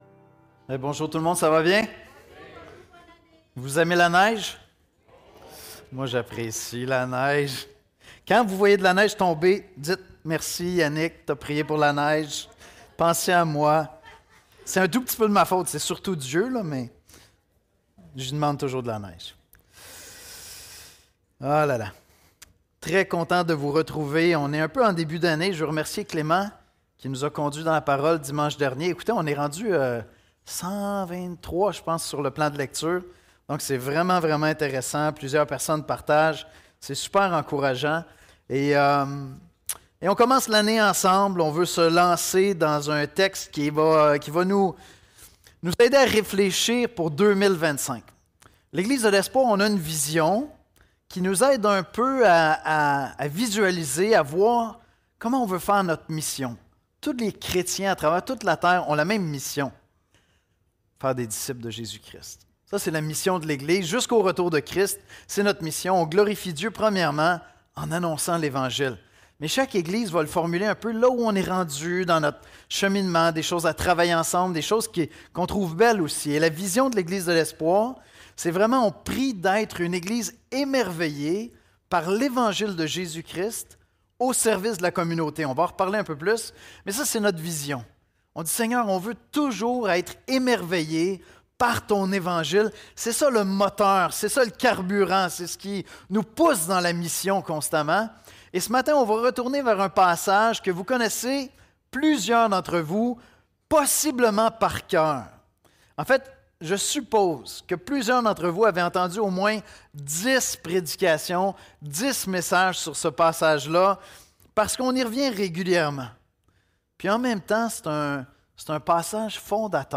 Nous vous présentons quelques unes des exposés bibliques apportés à l'Église de l'Espoir en baladodiffusion. Nous espérons que ceux-ci seront utiles pour vous dans votre recherche ou votre croissance spirituelle.